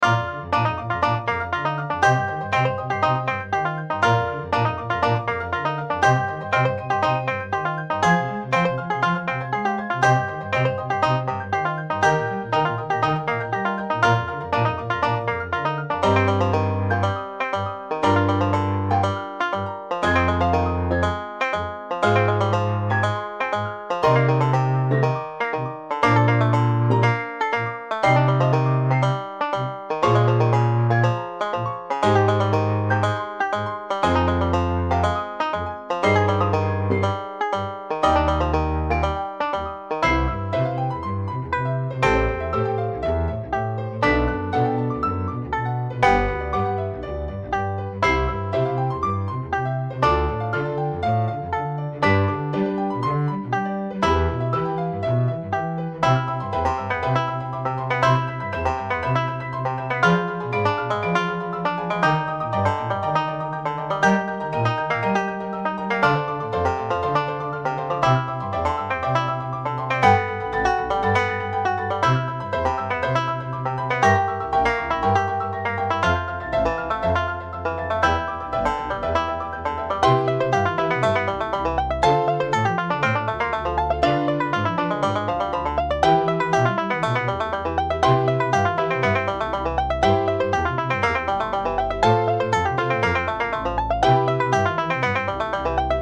BGM
ピアノ、バンジョー、コントラバス